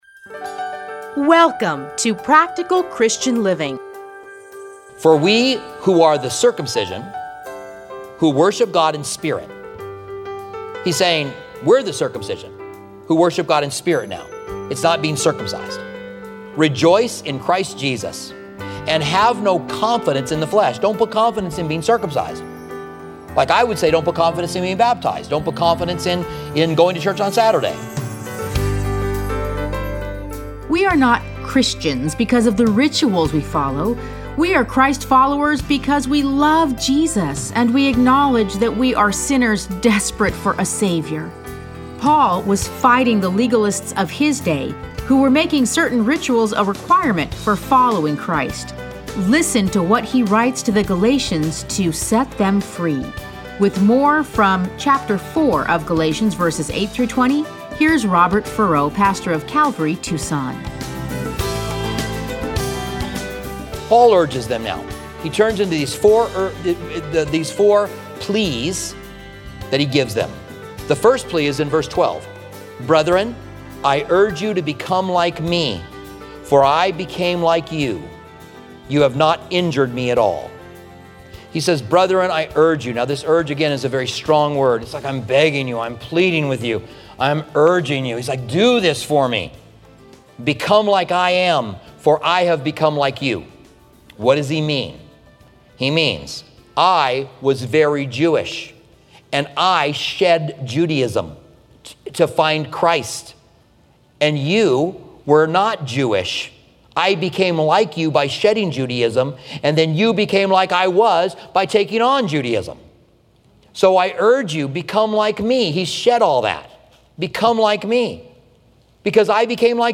Listen to a teaching from Galatians 4:8-20.